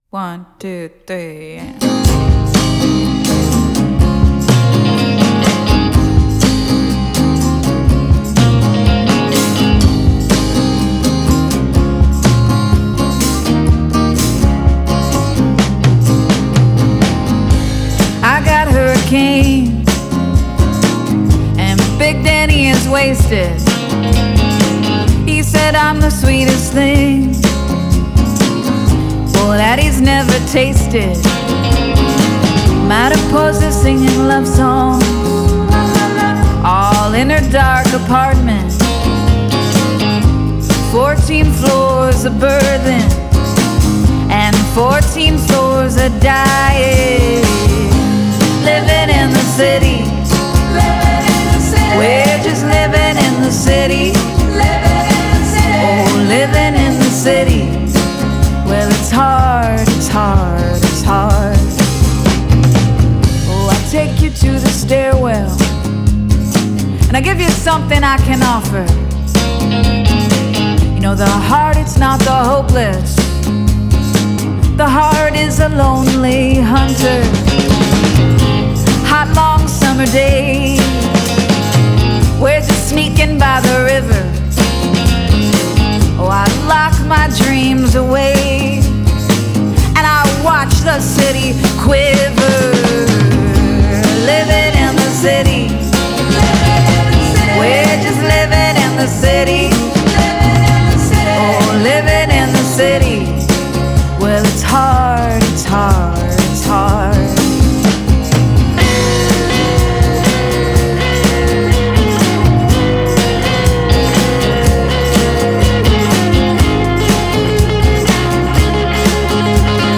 ardent folk and harmonious indie rock